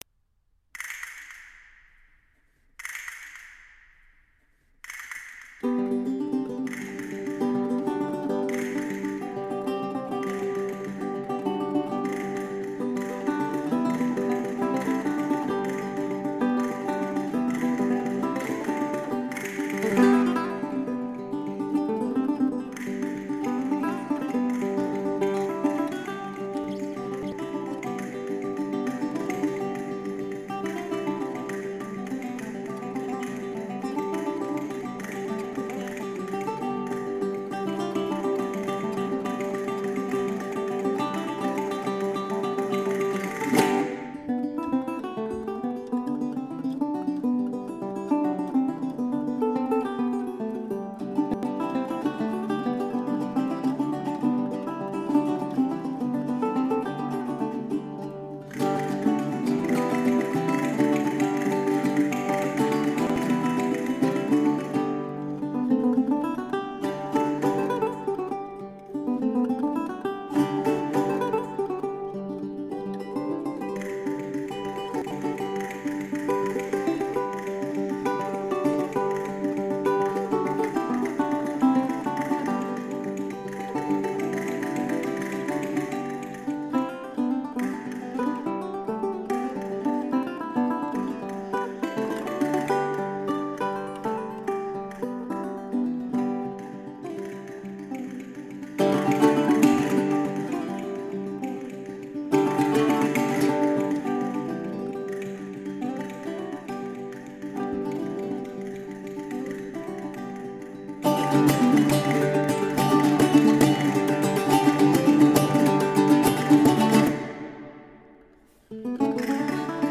THE BAROQUE GUITAR